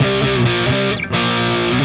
AM_HeroGuitar_130-C01.wav